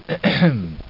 Ahem Sound Effect
ahem.mp3